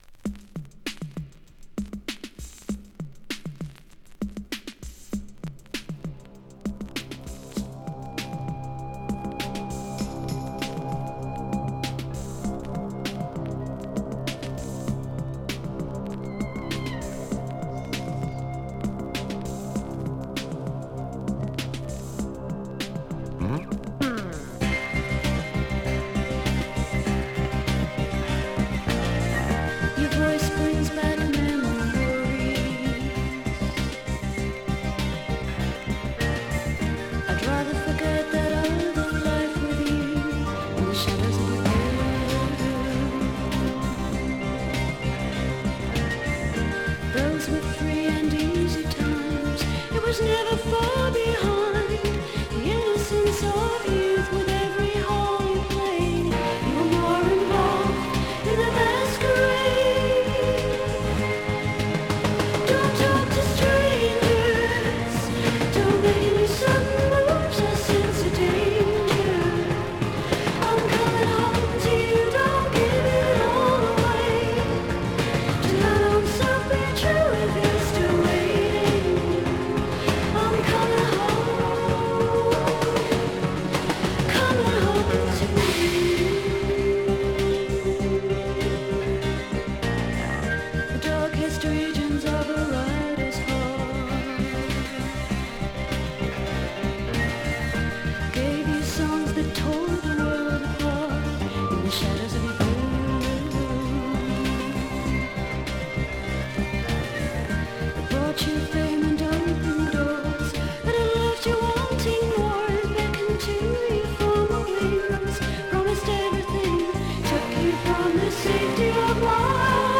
【SYNTH POP】